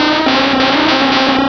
Cri de Noadkoko dans Pokémon Rubis et Saphir.